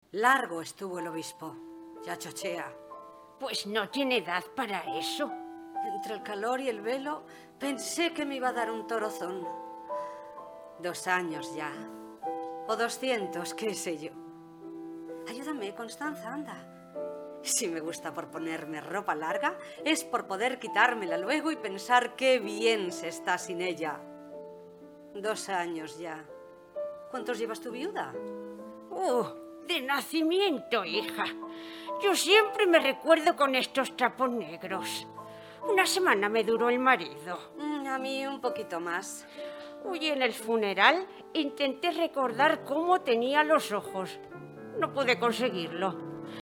Collage con la entrega de premios de Las manos a escenaPrimer premio: 2.000 euros al grupo, diploma y publicación en la Biblioteca Digital de la ONCE, al trabajo presentado por la agrupación ‘Teatro y punto’, con la interpretación de la obra